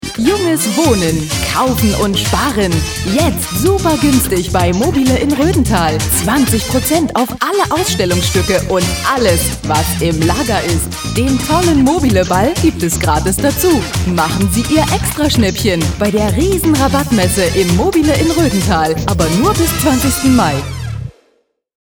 Sprecherin deutsch. Stimmalter Mitte 30. Sprecherin fĂŒr Werbung / OFF / Industriefilm / HĂ¶rspiel / Podcast
Sprechprobe: Sonstiges (Muttersprache):
female german voice over. Voice over artist for commercials, tv, radio, synchron, audio-books, documentaries, e-learning, podcast